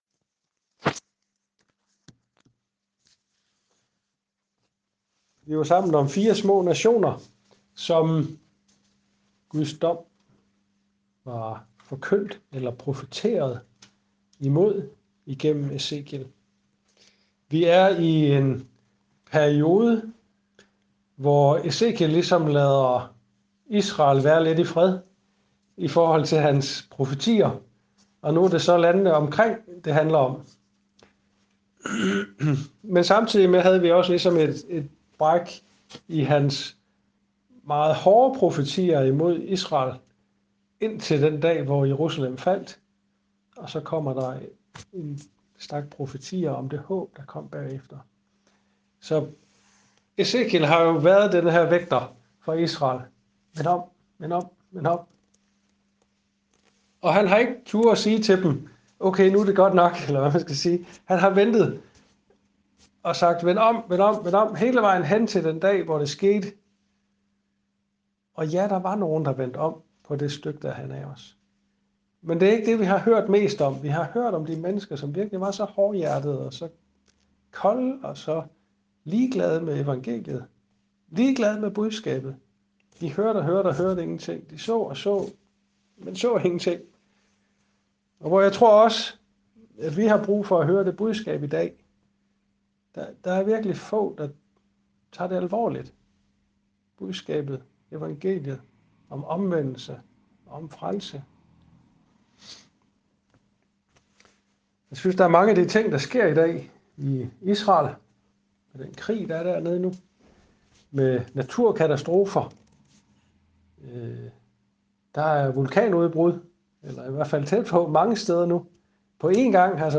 Taler